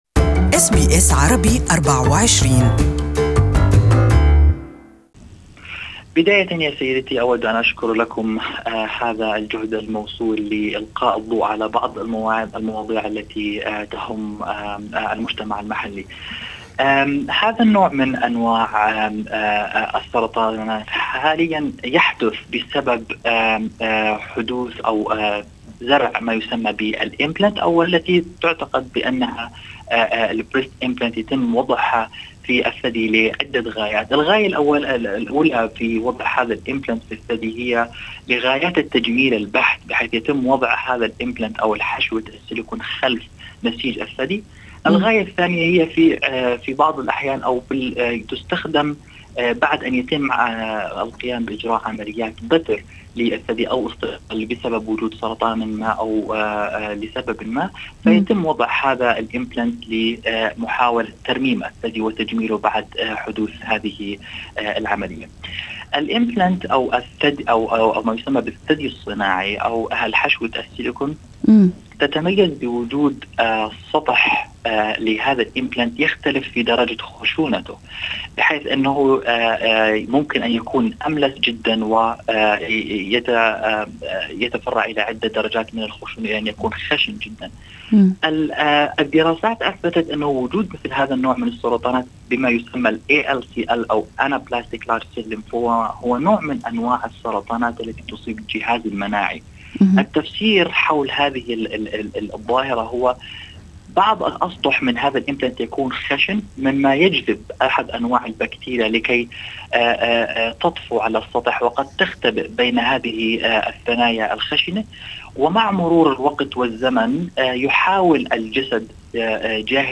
لمعرفة المزيد عن هذا الموضوع استضاف برنامج أستراليا اليوم